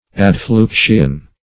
Adfluxion \Ad*flux"ion\, n.